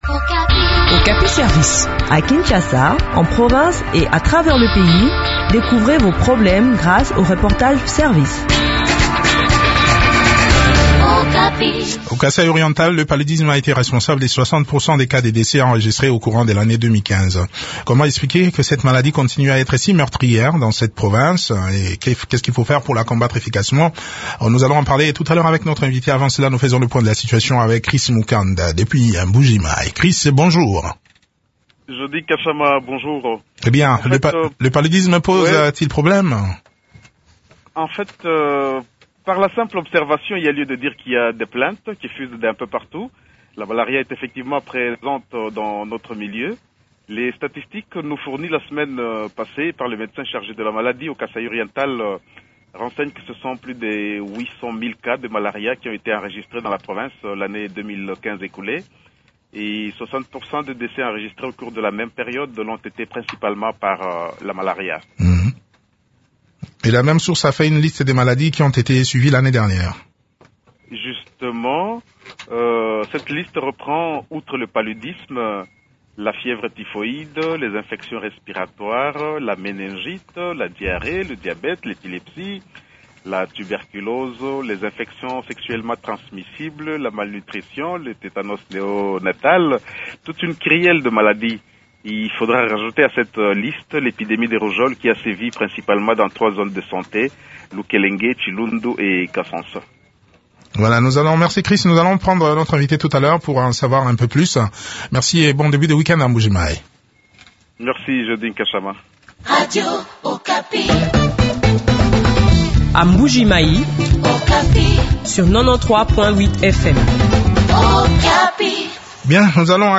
s’entretient sur ce sujet avec  Docteur